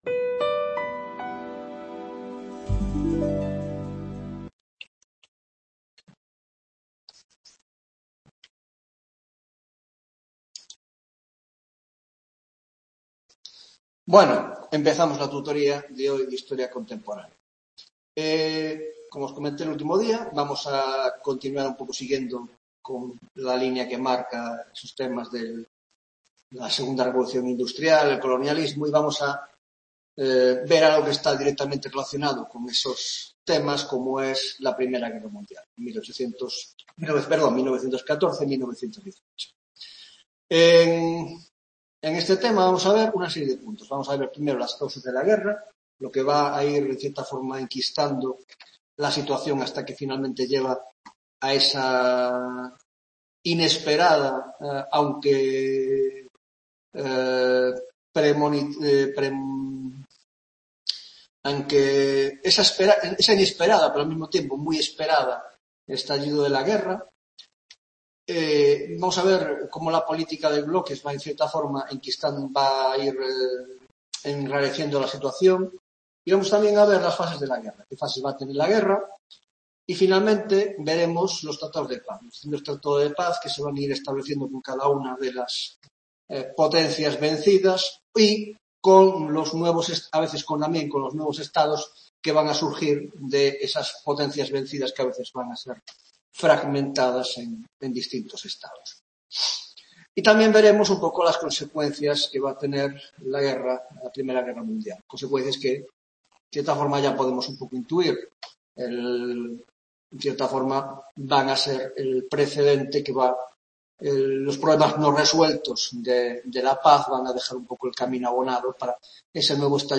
15ª Tutoría de Historia Contemporánea - La I Guerra Mundial: Introducción (1ª parte) - 1) Contexto previo: La política de bloques y la "Paz Armada" 1.2) las tensiones coloniales y los conflictos nacionales